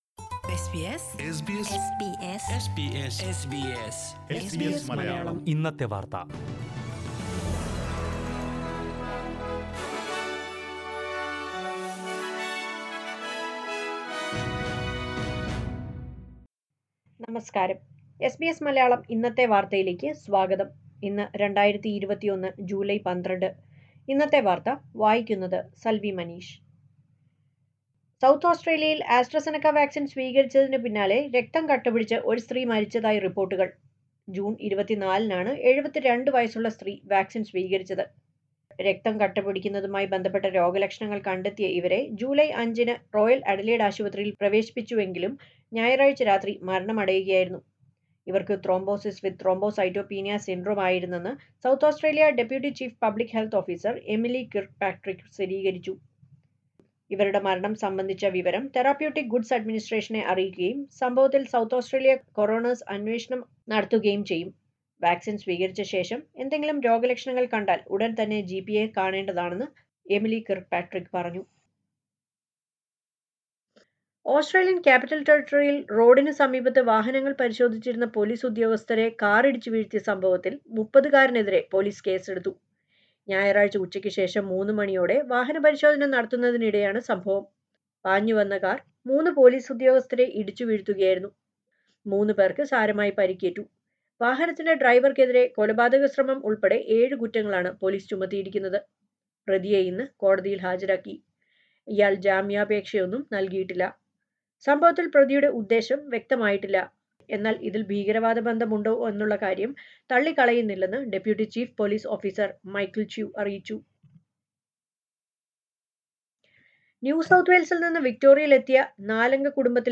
2021 ജൂലൈ 12ലെ ഓസ്ട്രേലിയയിലെ ഏറ്റവും പ്രധാന വാർത്തകൾ കേൾക്കാം...